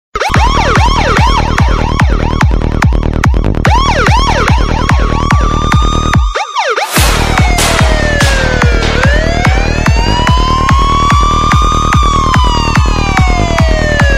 Police Cars Sirens Mix Sounds Effects ringtone free download
Sound Effects